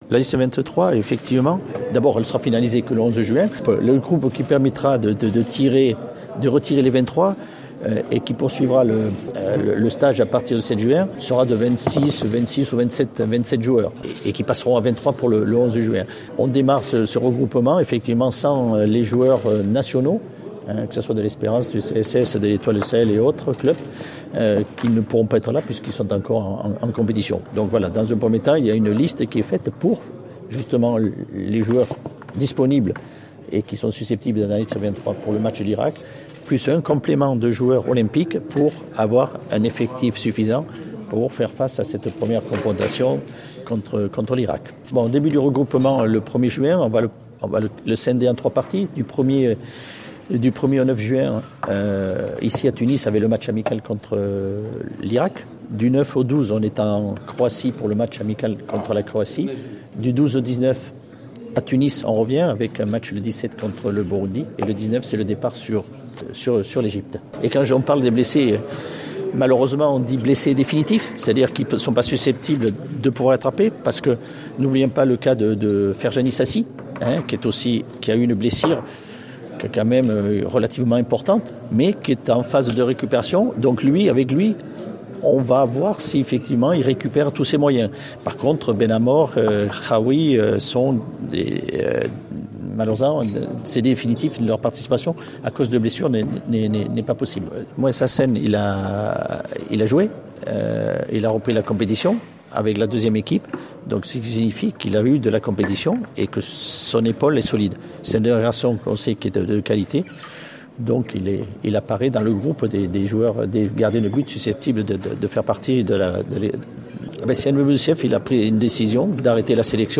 تحدث المدرب الوطني خلال الندوة الصحفية عن الظروف المعقدة التي تعامل معها لتحديد اللائحة الأولية التي تقتصر على 14 لاعبا ينشطون في البطولات الأوروبية بالإضافة إلى 7 عناصر أولمبية لمواجهة العراق في أول مباراة إعدادية في برنامج المنتخب الوطني يوم 7 جوان القادم.